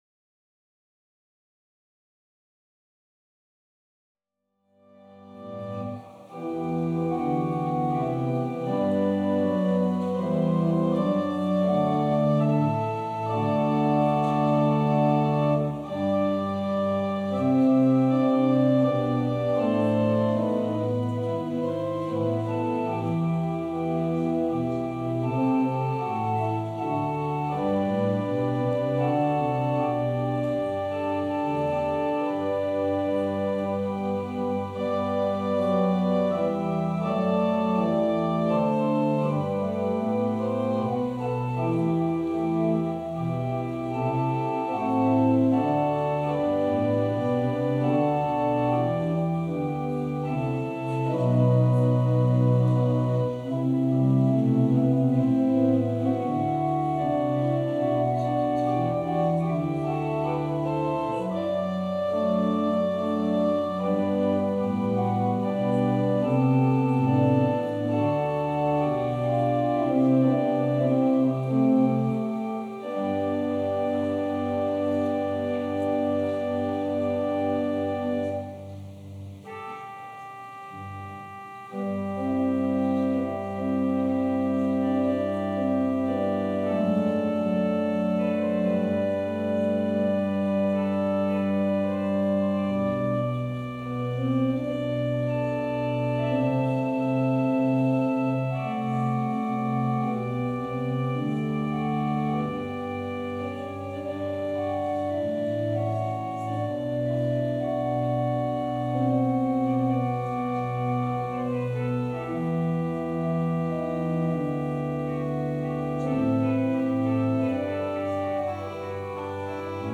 Passage: Luke 1: 39-45 Service Type: Holy Day Service Scriptures and sermon from St. John’s Presbyterian Church on Sunday